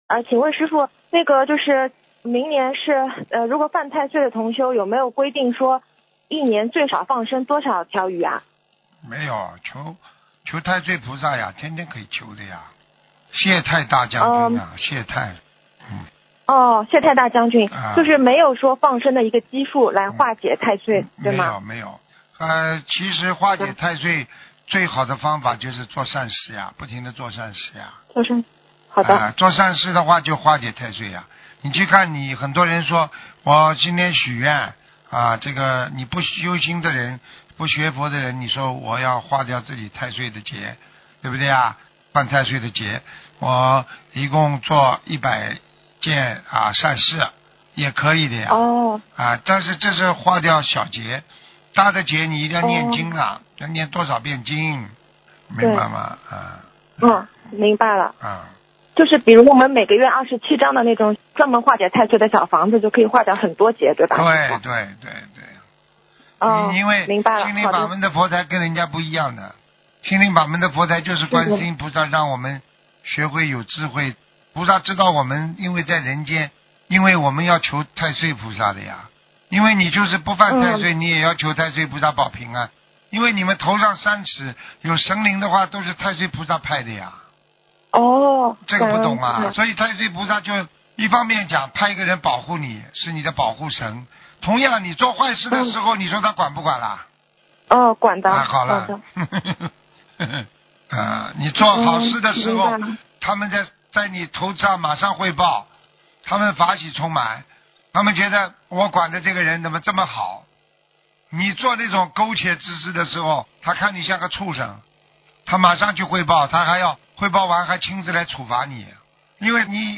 ▶ 语 音 朗 读